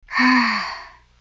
sigh1.wav